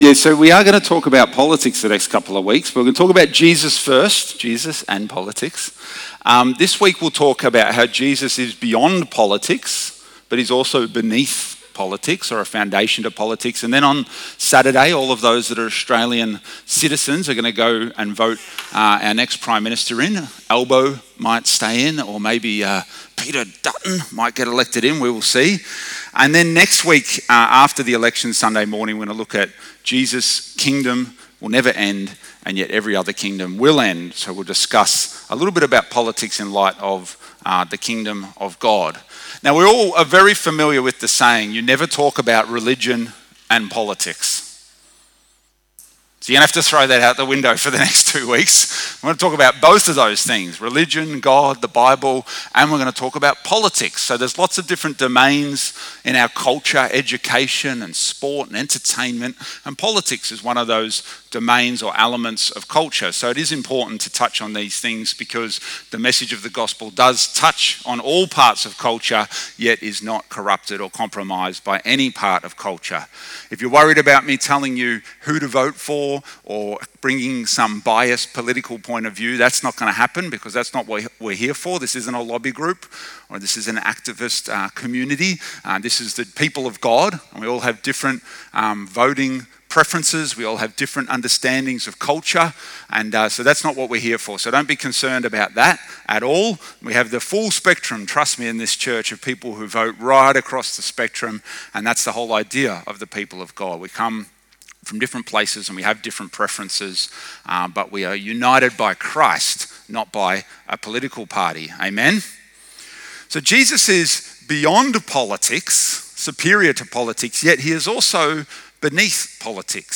This two-part sermon tackles the socially prohibited topics of Religion and Politics as the Federal election approaches.